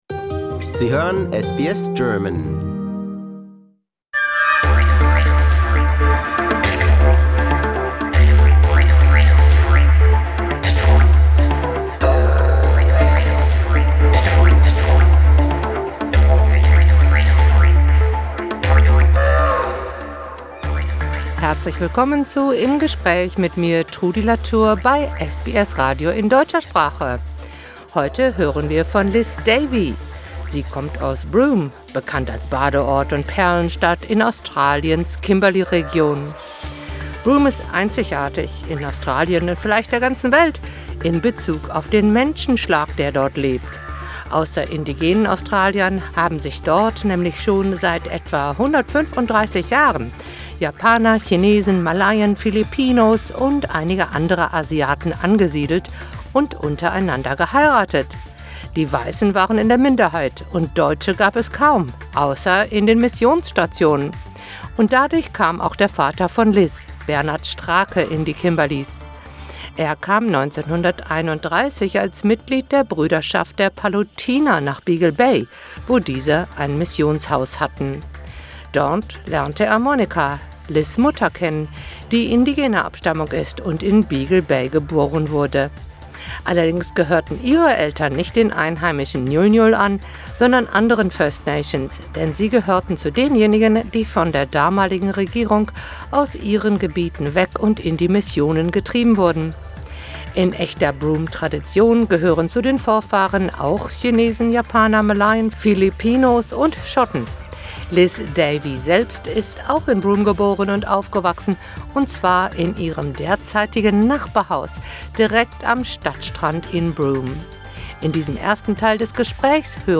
We'll find out more about that soon, in the second part of the interview.